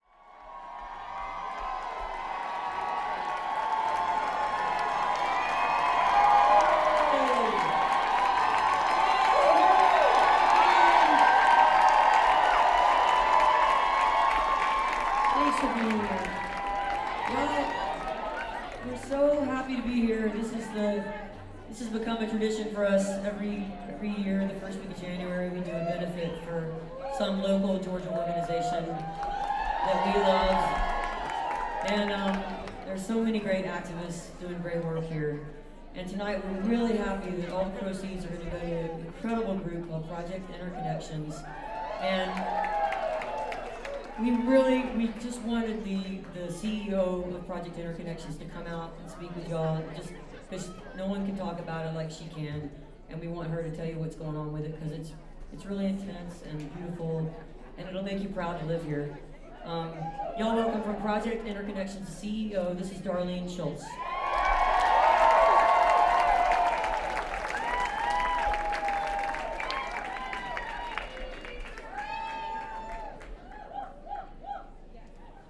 lifeblood: bootlegs: 2017-01-04: terminal west - atlanta, georgia (benefit for project interconnections)
01. amy ray greeting (1:15)